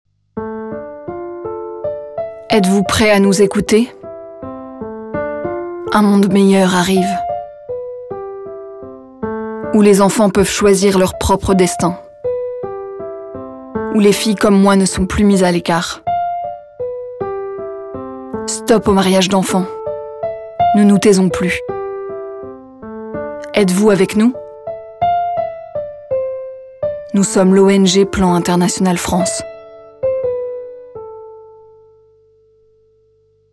Voix off
6 - 40 ans - Soprano